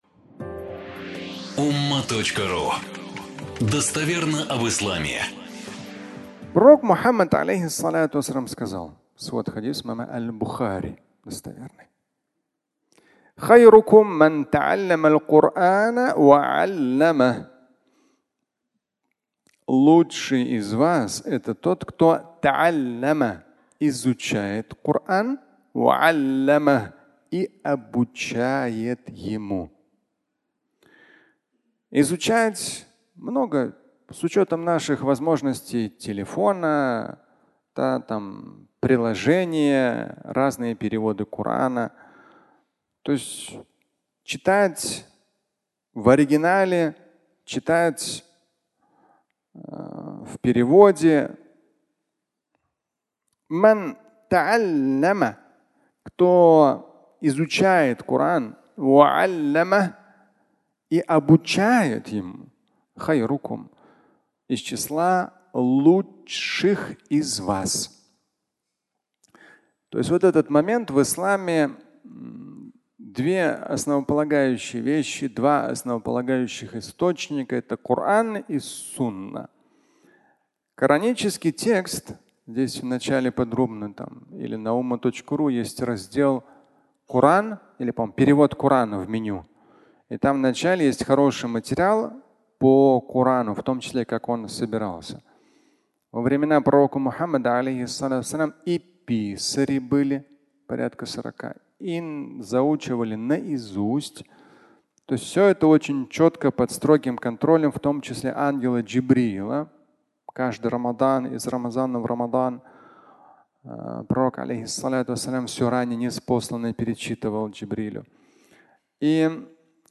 Изучает и обучает (аудиолекция)